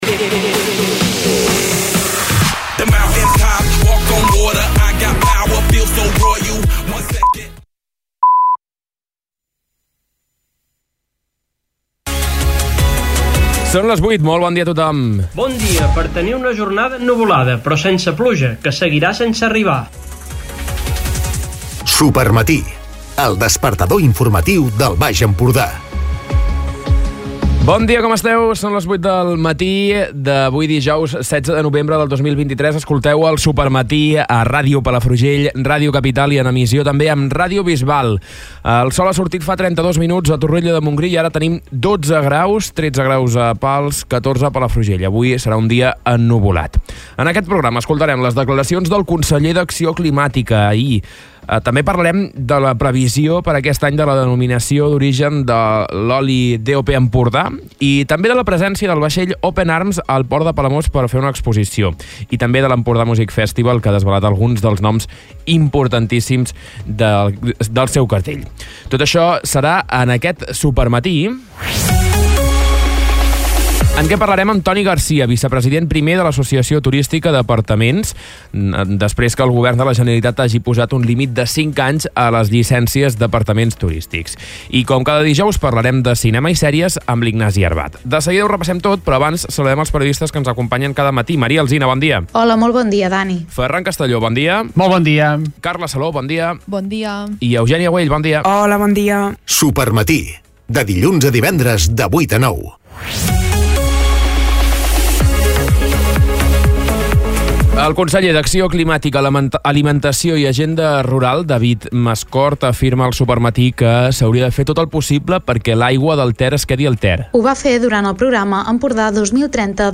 Escolta l'informatiu d'aquest dijous